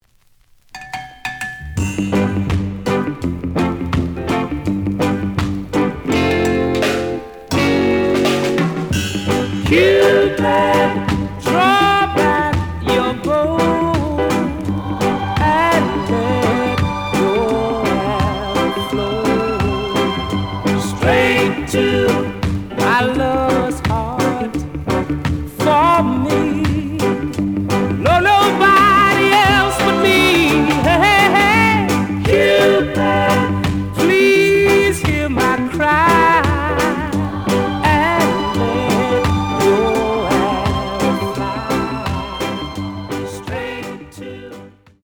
The audio sample is recorded from the actual item.
●Format: 7 inch
●Genre: Rock Steady